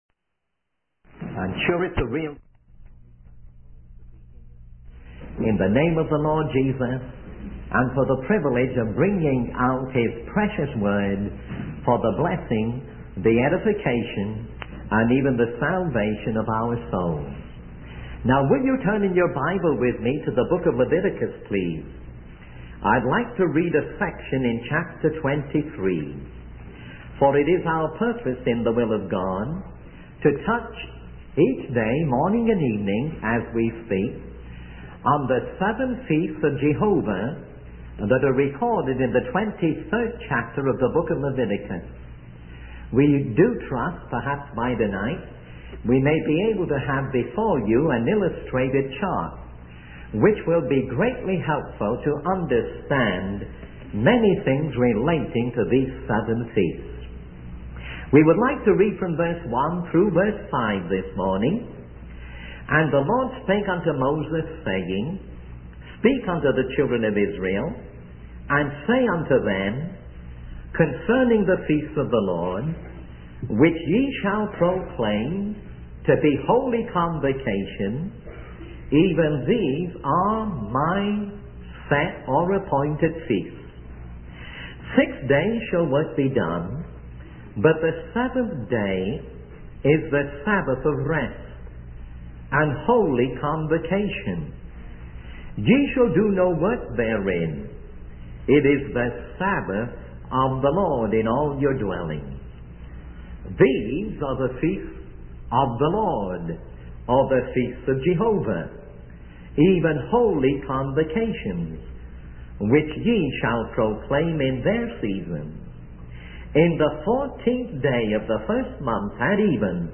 In this sermon, the speaker provides an outline of the book of Leviticus and its spiritual application to our lives today. The speaker emphasizes the importance of understanding the feasts of Jehovah, which were divine appointments for God's people to come together for His pleasure.